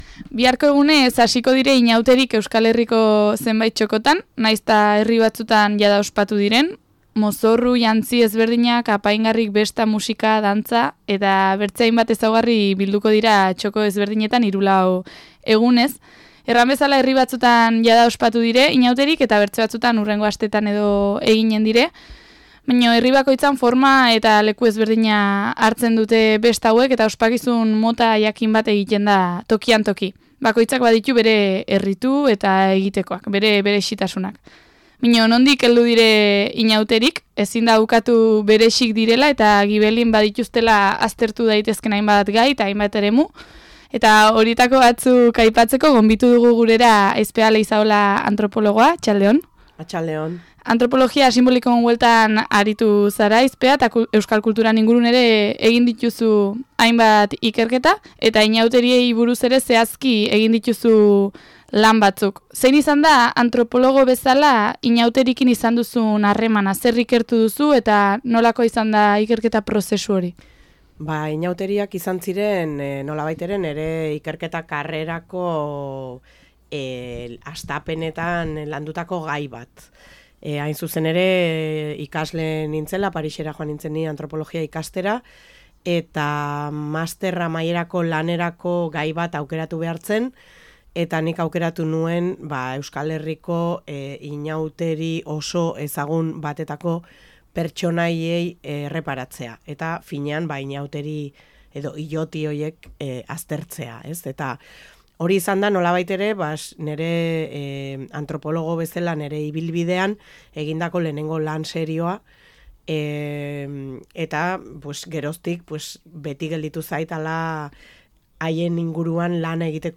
Eguneko elkarrizketa